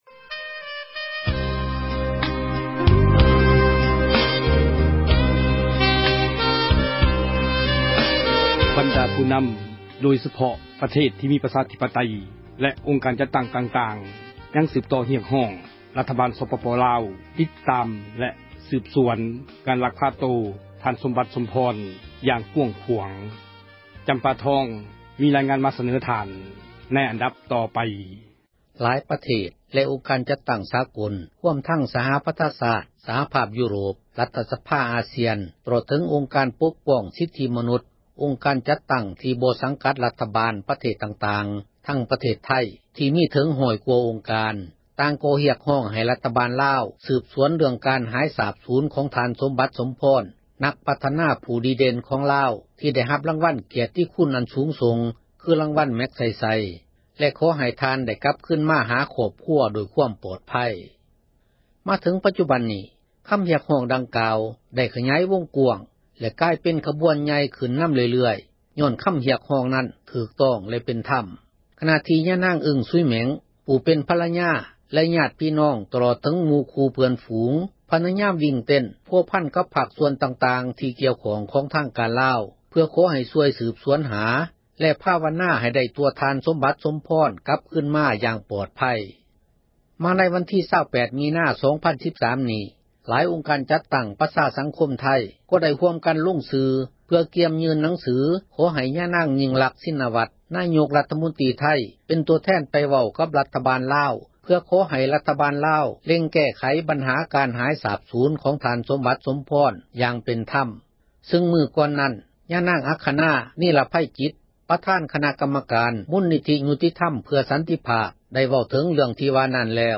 ມີຣາຍງານ ມາສເນີທ່ານ